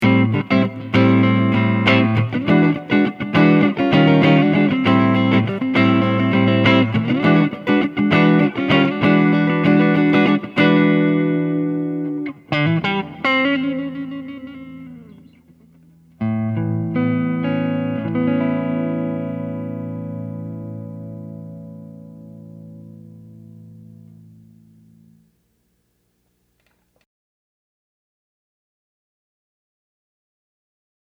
I just recorded in an open room with no filtering. BTW, I used a Strat with a prototype Aracom RoxBox 18 Watt Amp with a Jensen 1 X10 speaker.
Modulated
rv7_modulated.mp3